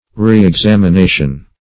Reexamination \Re`ex*am`i*na"tion\ (-?*n?"sh?n), n.